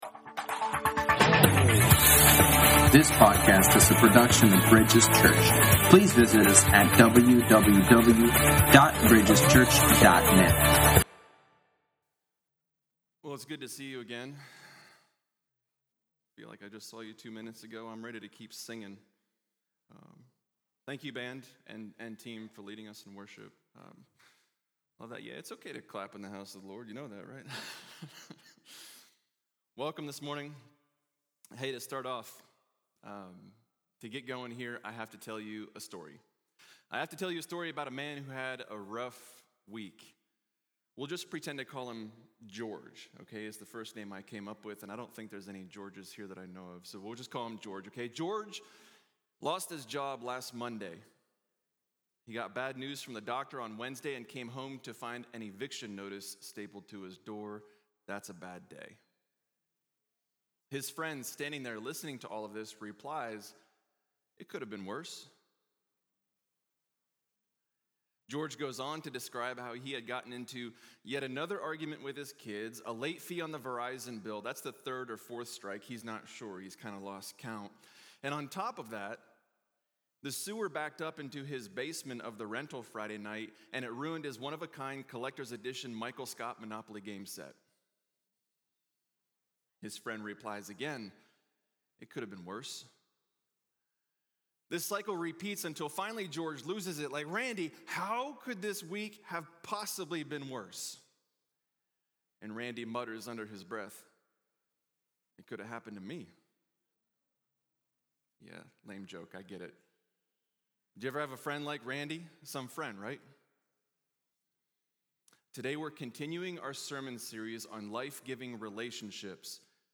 Sermons | Bridges Church